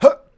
hurt10.wav